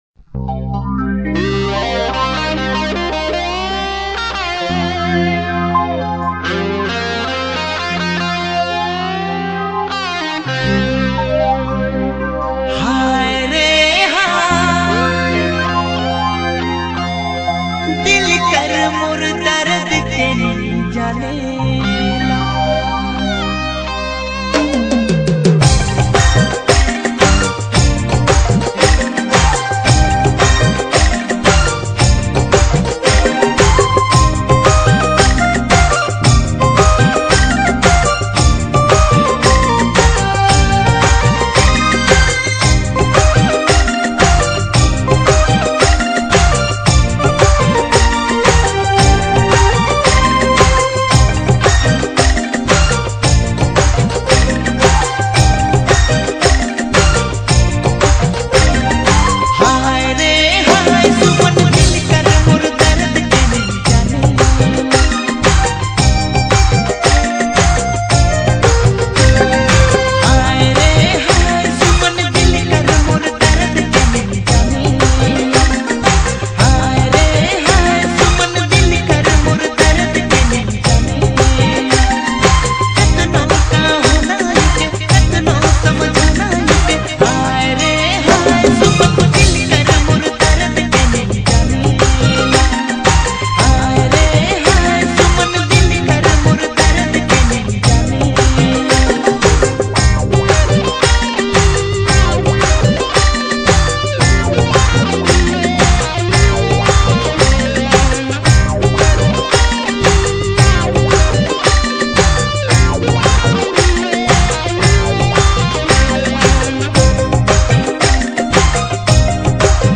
a soulful Nagpuri song